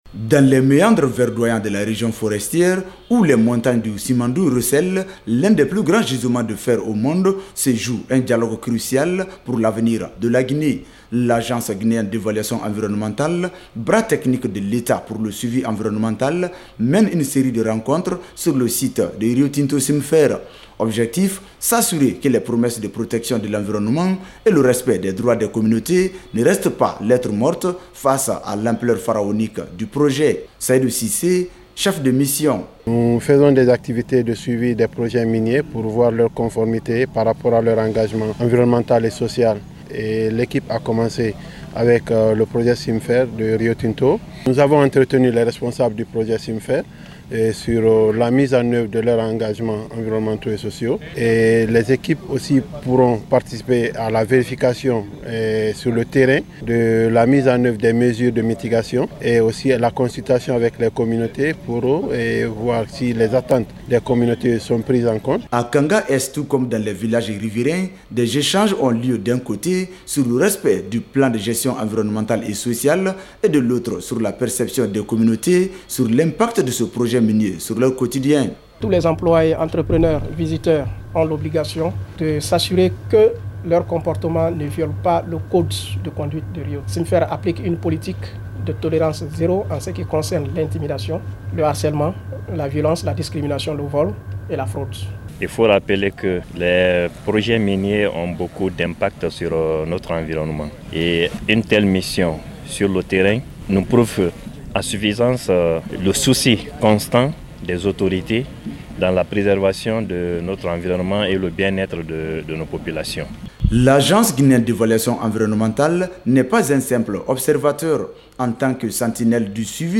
Le récit de notre Correspondant Régional